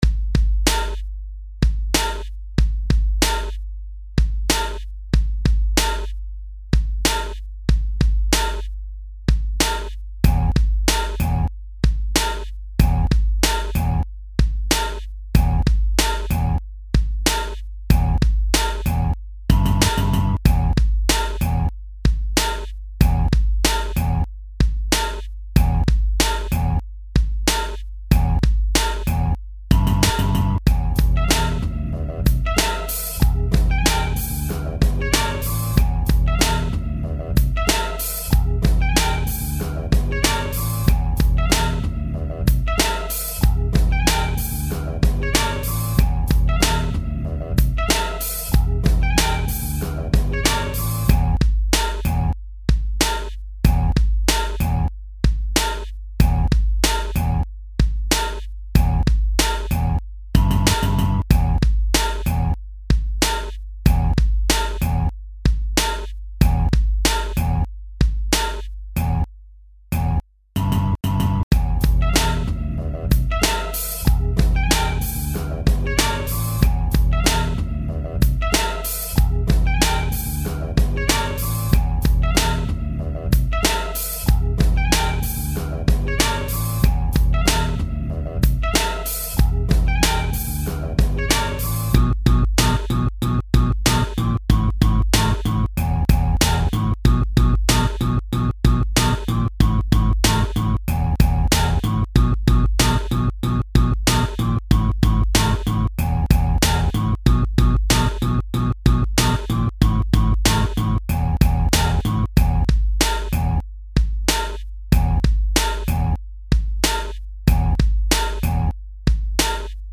Instrumental - Lyrics